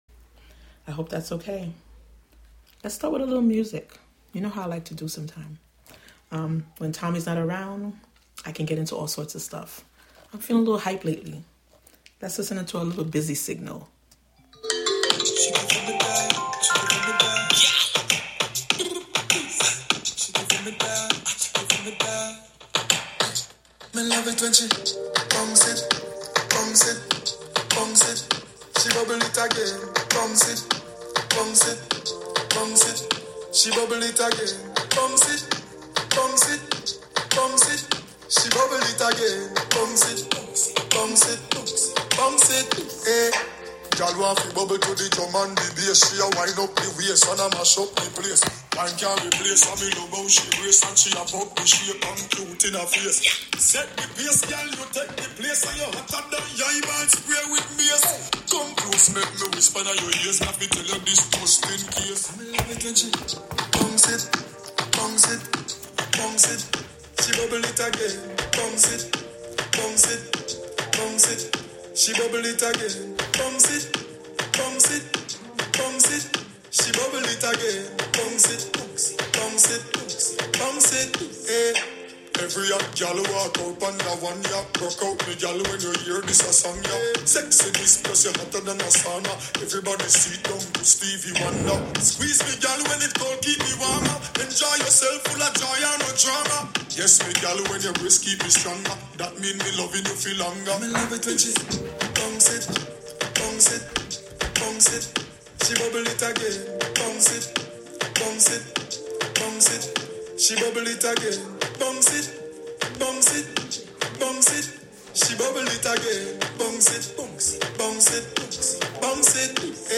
Broadcasting live from either The Spark of Hudson , the Hudson Thursday Market , or other locations out and about in the community.